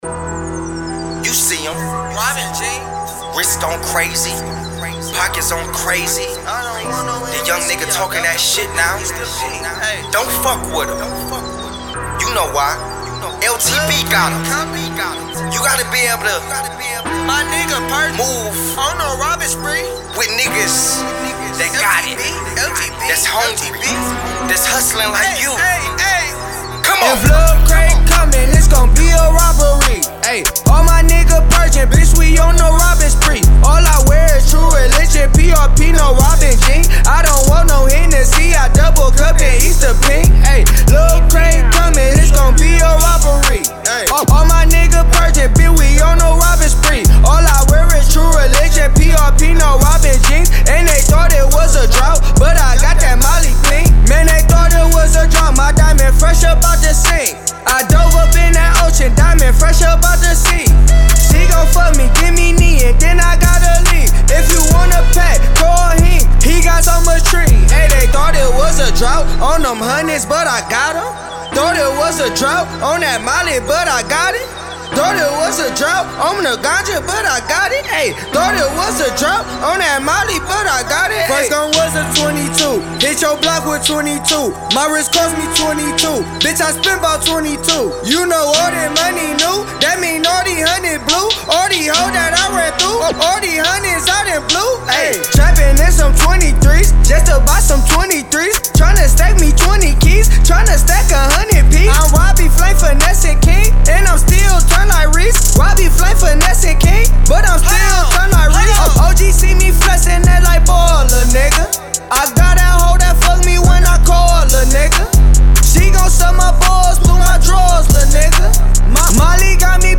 heavy street banger